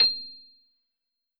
piano-ff-68.wav